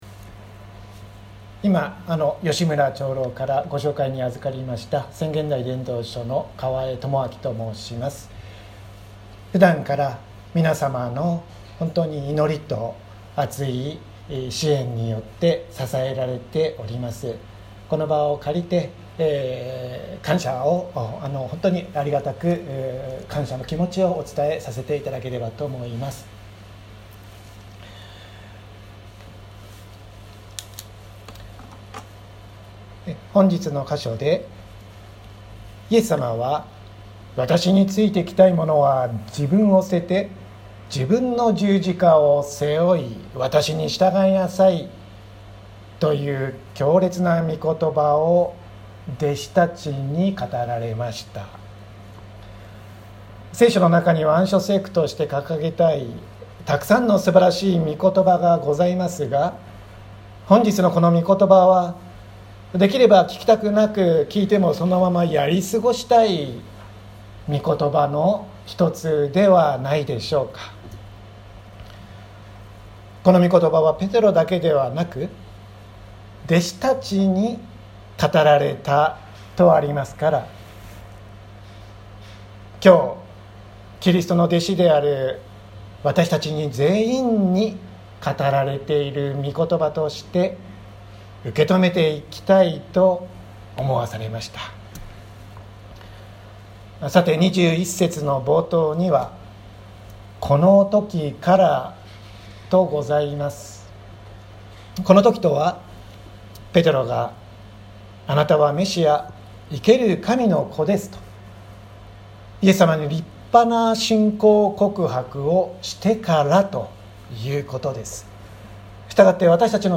音声ファイル 礼拝説教を録音した音声ファイルを公開しています。